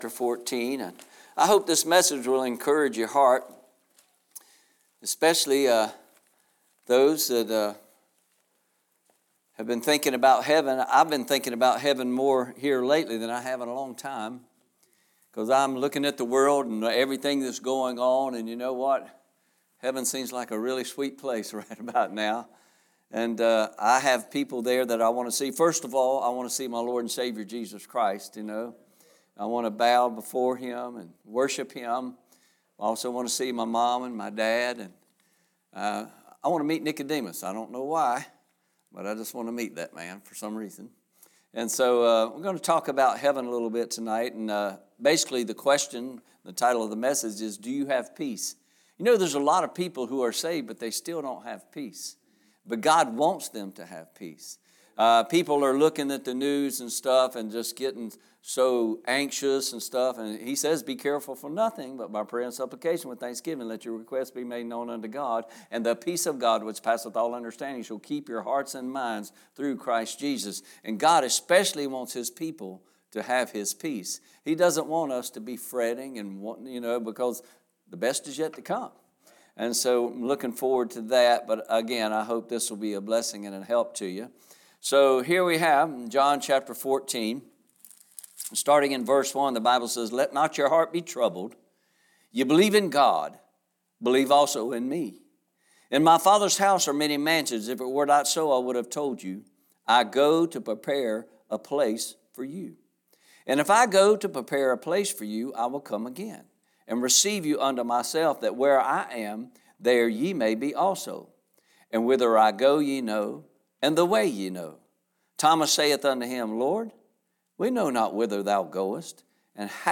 From the pulpit of Bethel Baptist Church